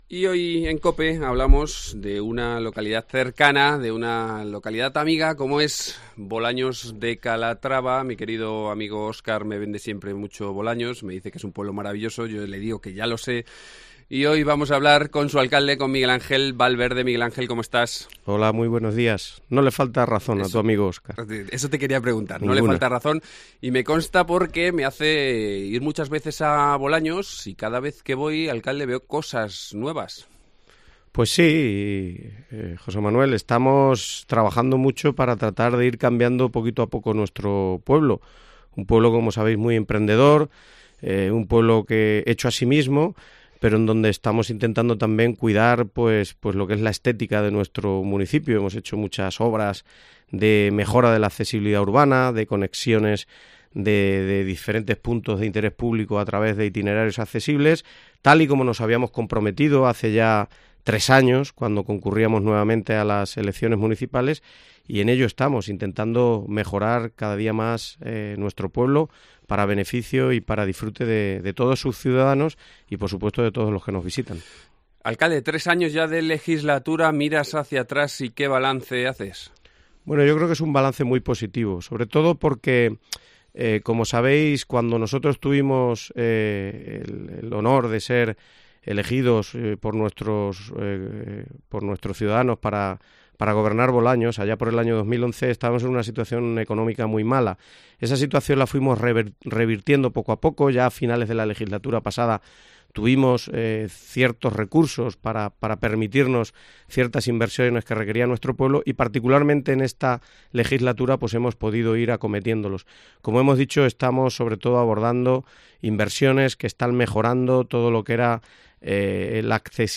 Hoy nos visita aquí en los estudios el alcalde de Bolaños de Calatrava, Miguel Ángel Valverde, y con él repasamos toda la actualidad bolañega.